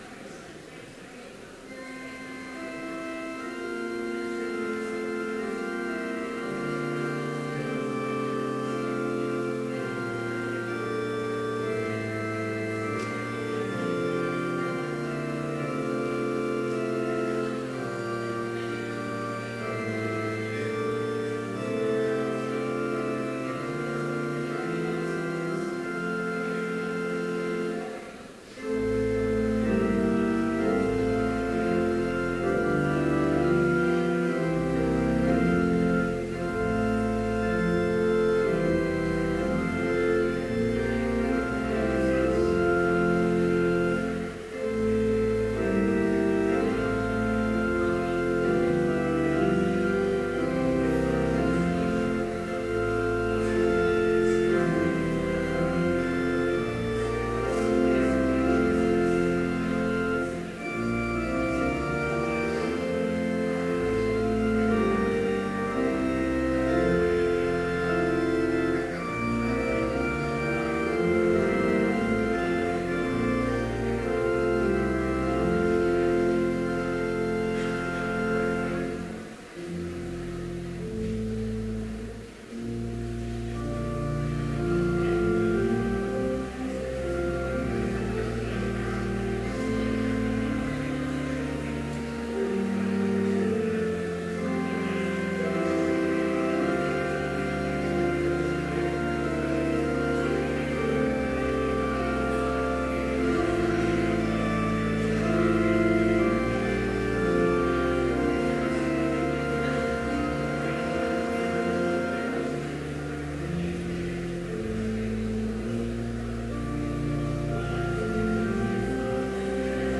Complete service audio for Chapel - January 13, 2015
Prelude Hymn 174, Children of the Heavenly Father Reading: Luke 2:46-52 ESV Homily Prayer Hymn 172, Songs of Thankfulness and Praise Blessing Postlude